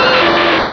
Cri de Magmar dans Pokémon Rubis et Saphir.